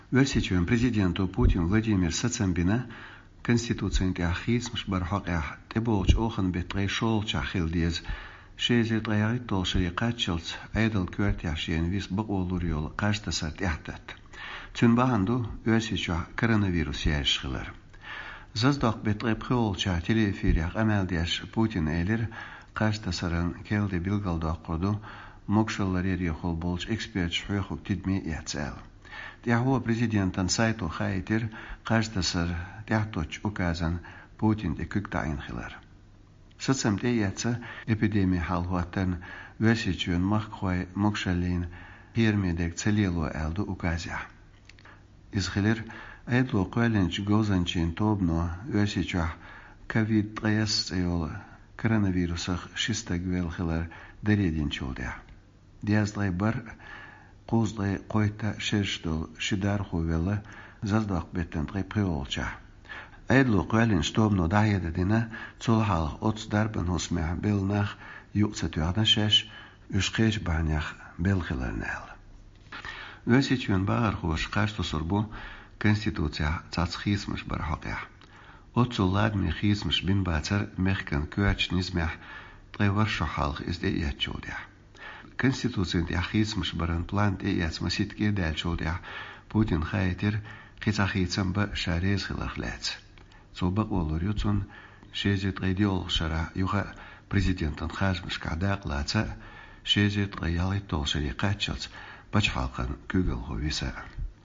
Телеэфирехь къамел деш ву Путин Владимир